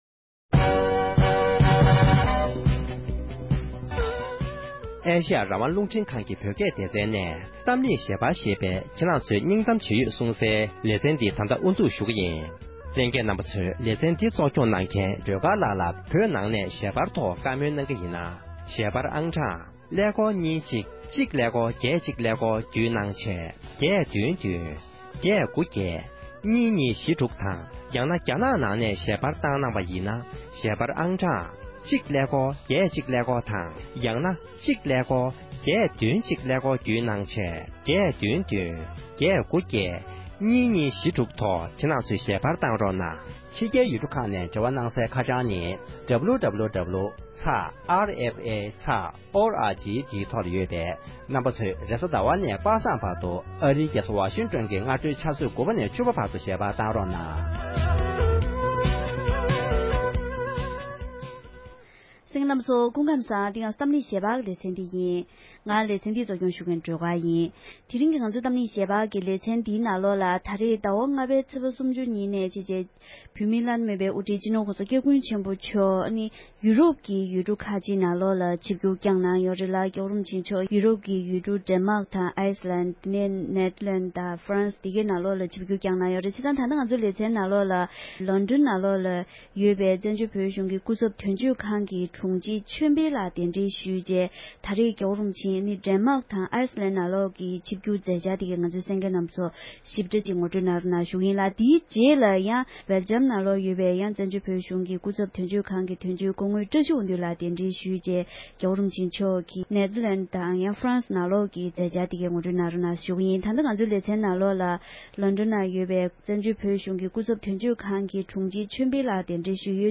༄༅༎དེ་རིང་གི་གཏམ་གླེང་ཞལ་པར་གྱི་ལེ་ཚན་ནང་དུ་བོད་མིའི་བླ་ན་མེད་པའི་དབུ་ཁྲིད་སྤྱི་ནོར་༸གོང་ས་༸སྐྱབས་མགོན་ཆེན་པོ་མཆོག་ཡོ་རོབ་ཀྱི་ཡུལ་གྲུ་བཞིའི་ནང་ཆིབས་བསྒྱུར་བསྐྱངས་གནང་མཛད་པའི་ཞིབ་ཕྲའི་གནས་ཚུལ་ཁག་ངོ་སྤྲོད་ཞུས་པའི་ལེ་ཚན་དང་པོར་གསན་རོགས༎